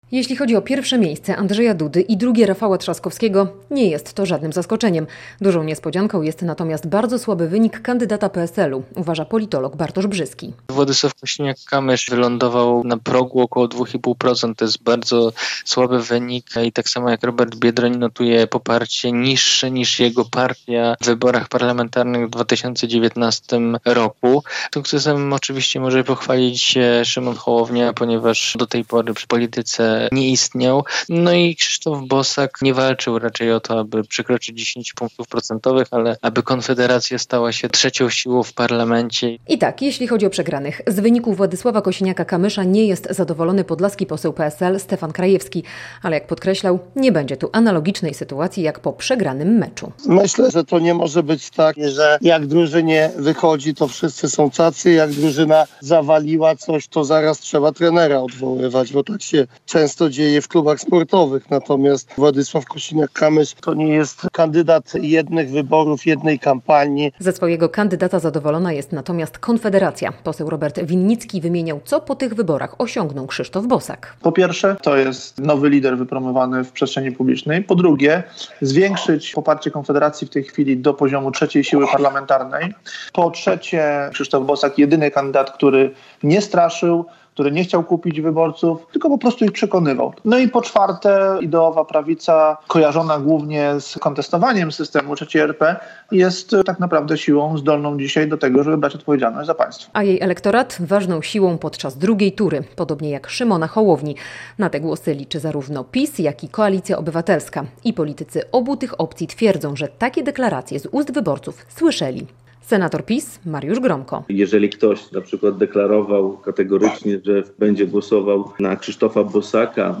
Podlascy politycy komentują sondażowe wyniki wyborów